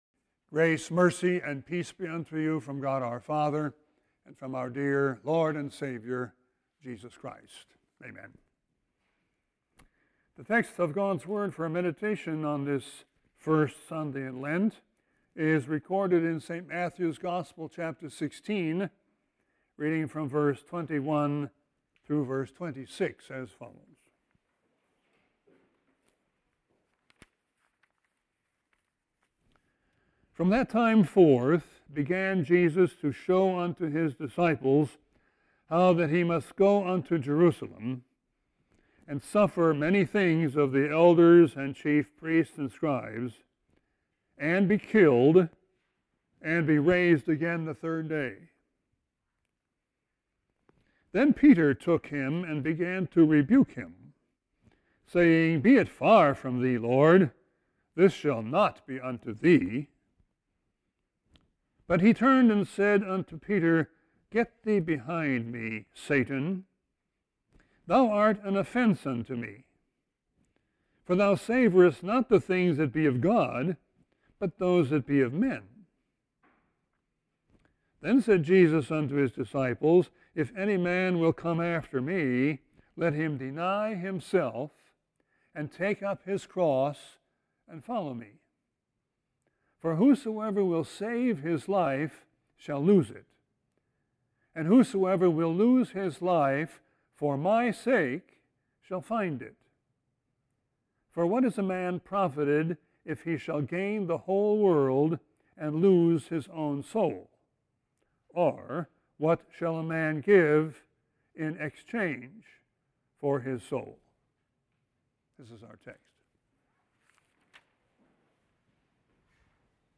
Sermon 2-21-21.mp3